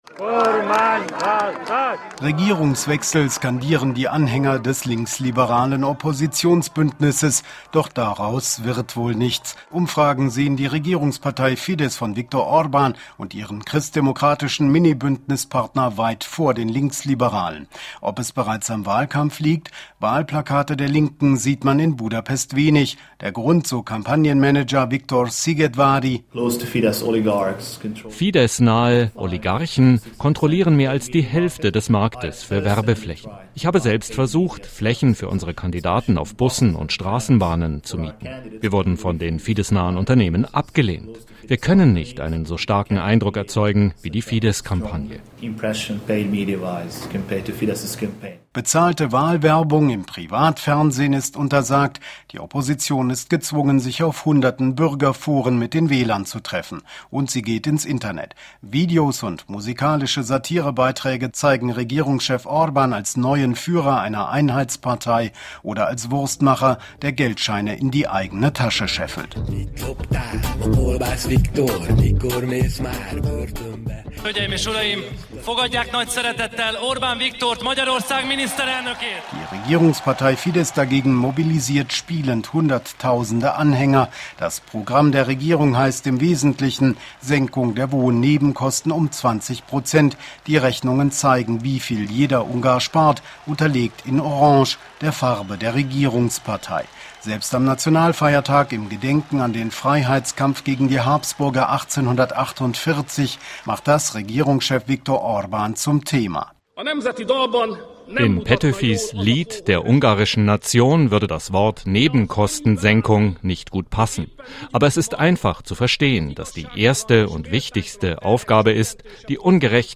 Wahlkampf-Reportage